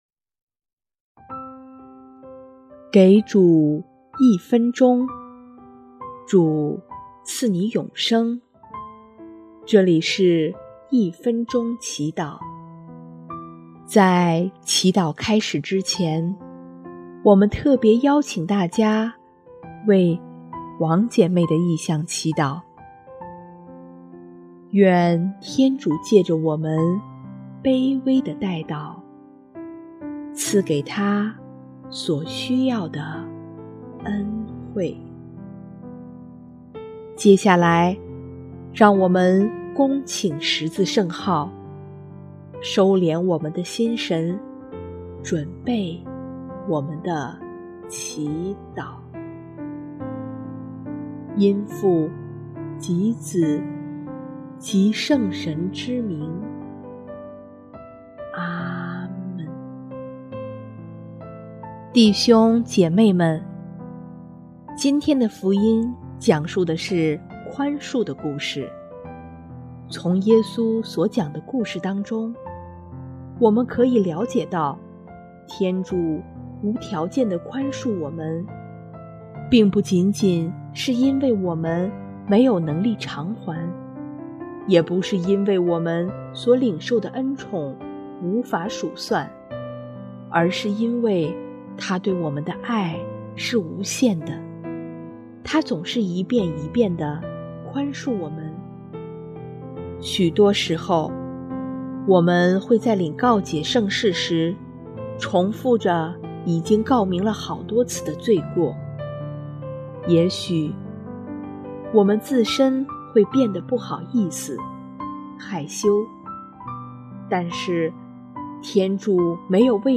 【一分钟祈祷】|8月17日 因从祂领受了宽恕，我们也宽恕他人！